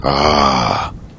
zen_ahhhhhh.wav